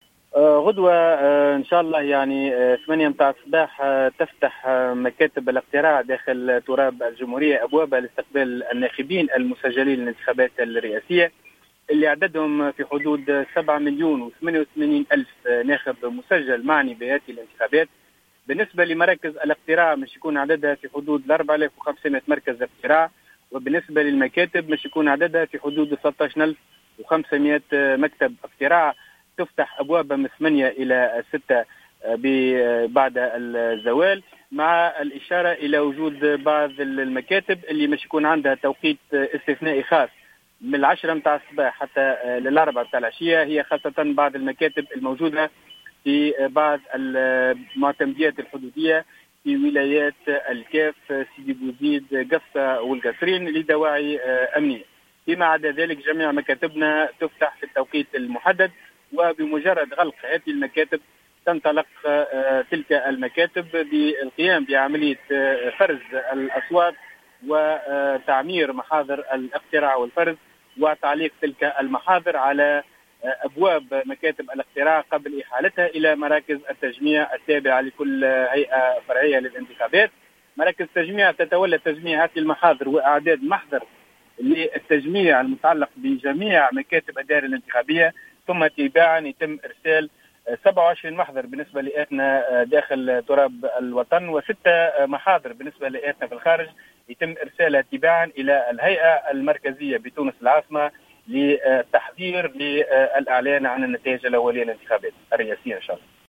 أفاد عضو الهيئة العليا المستقلة للانتخابات، فاروق بوعسكر، في تصريح لـ "الجوهرة اف أم"، مساء اليوم السبت، بأن مكاتب الاقتراع تفتح أبوابها غدا الأحد 15 سبتمبر 2019 بمناسبة الانتخابات الرئاسية السابقة لأوانها، بداية من الساعة الثامنة صباحا وإلى غاية السادسة مساء.